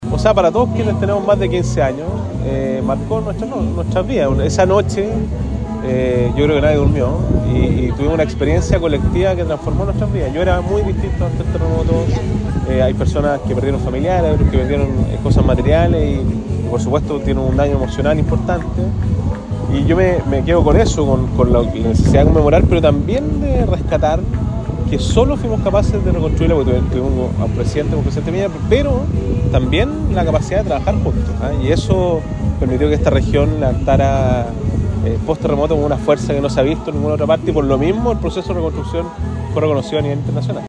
Durante la mañana de este jueves 27 de febrero, el gobernador del Biobío, Sergio Giacaman, se trasladó hasta la Plaza de la Ciudadanía de Dichato, para participar de una ceremonia de conmemoración del terremoto del 27 de febrero de 2010, organizada por el Comité de Vivienda de esa localidad.